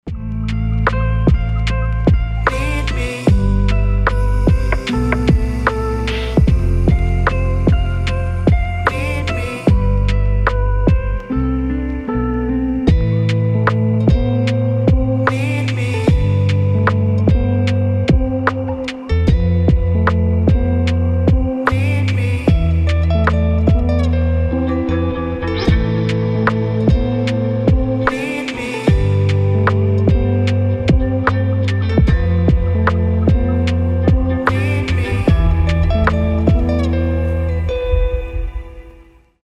• Качество: 320, Stereo
лирика
мелодичные
спокойные